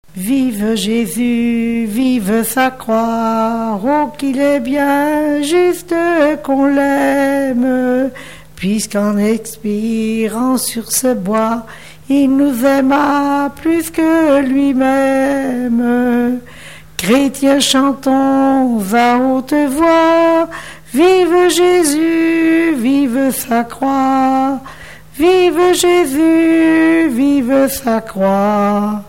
chanté à la fête du Christ-Roi
Genre strophique
Pièce musicale inédite